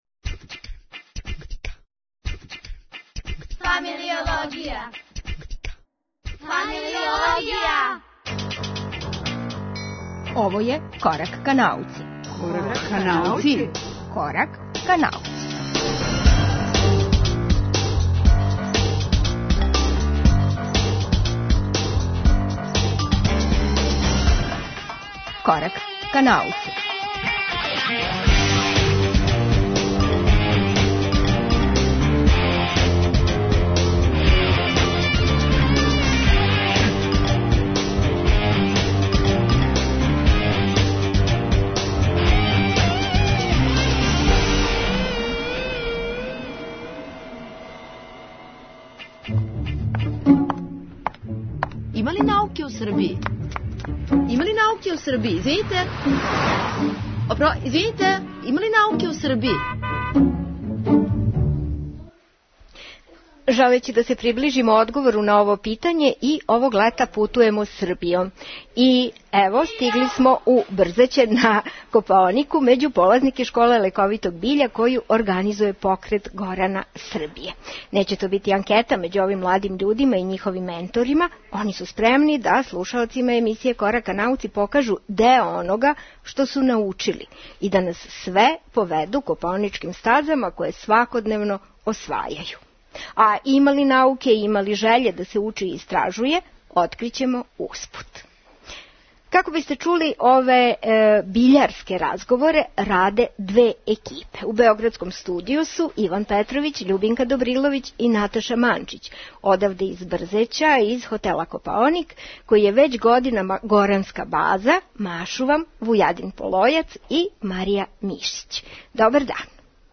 Емисију реализујемо из Брзећа на Копаонику, из горанске школе лековитог биља.
Саговорници су нам најмлађи припадници наше најстарије еколошке организације и њихови предавачи, ботаничари и шумарски инжењери.